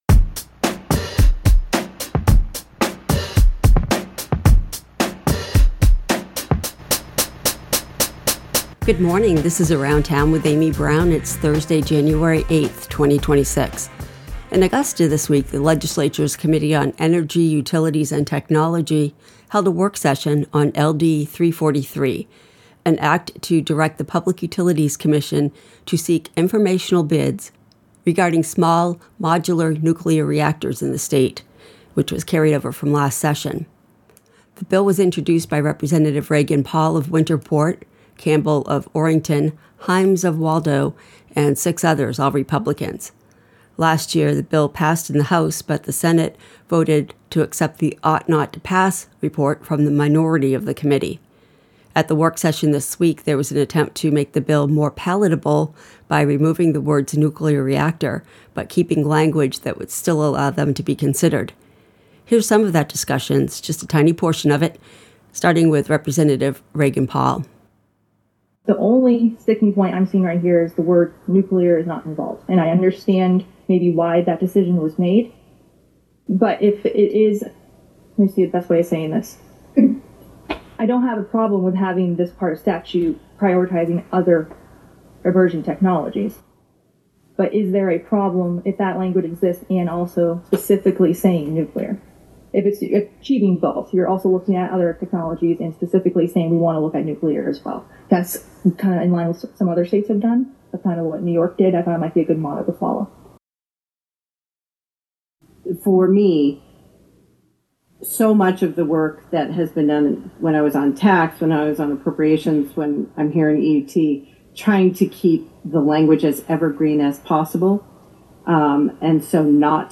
Here are some clips from the discussion at the work session.